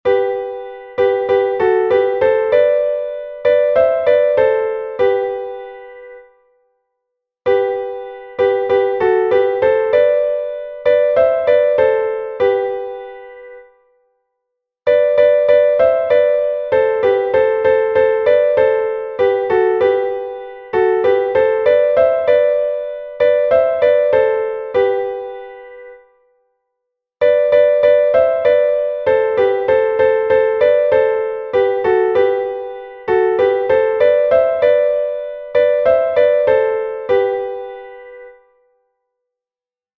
hudba:Chodská lidová